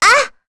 Rehartna-Vox_Damage_02.wav